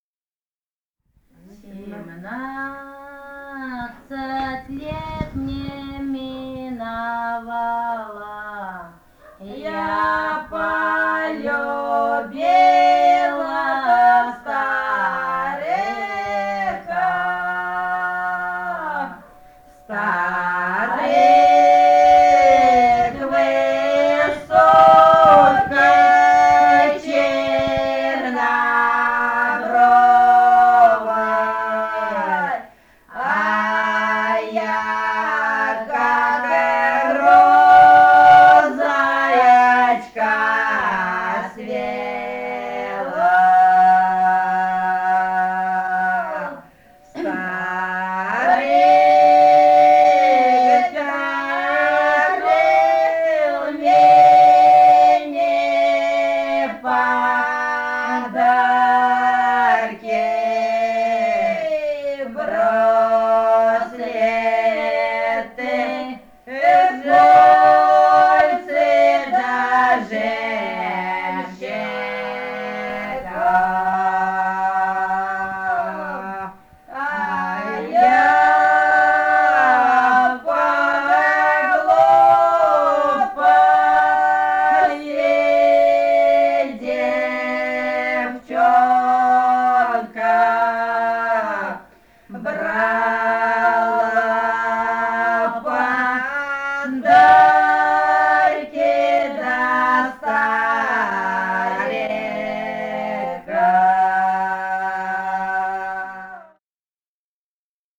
Этномузыкологические исследования и полевые материалы
Бурятия, с. Петропавловка Джидинского района, 1966 г. И0903-18